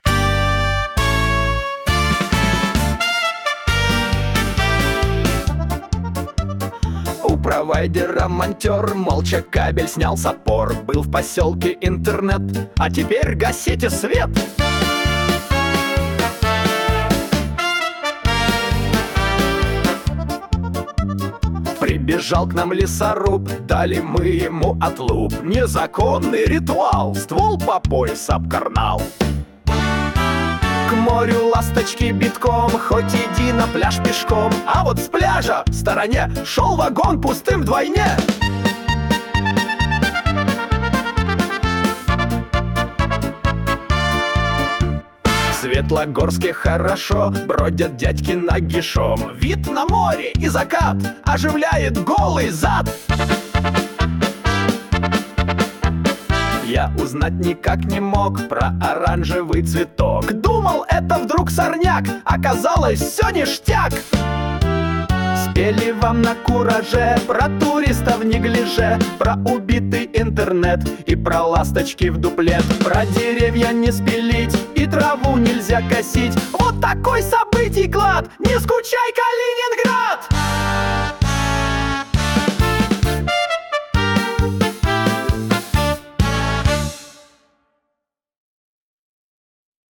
Куплеты на злобу дня о главных и важных событиях